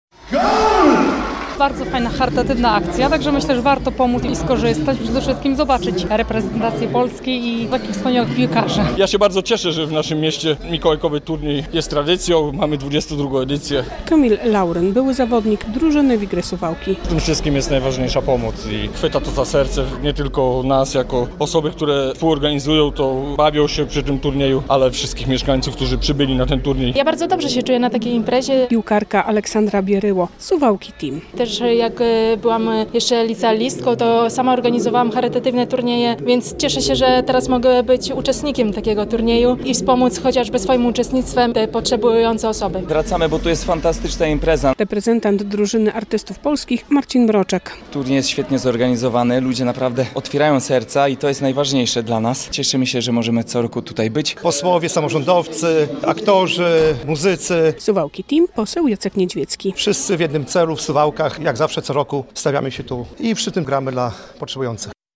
relacja
W hali sportowo-widowiskowej "Suwałki Arena" zmagały się ze sobą 4 drużyny: